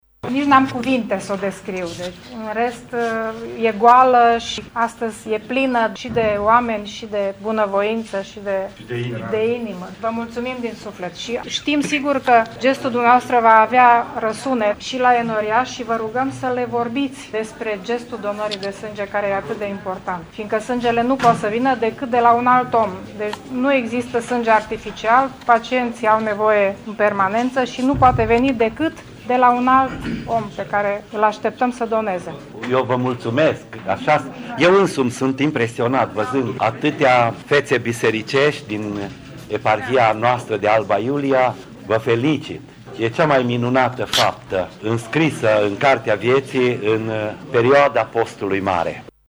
Preoţii sosiţi la Tîrgu Mureş pentru a dona sânge au spus că au ascultat de îndemnul lansat de Arhiepiscopia Alba Iuliei de a face o faptă bună în Postul Mare.